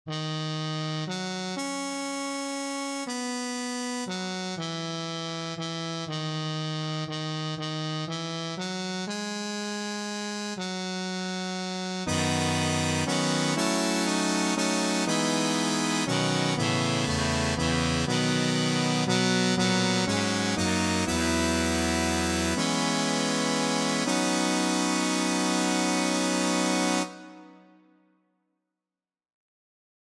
Key written in: B Major
How many parts: 4
Type: Barbershop
All Parts mix: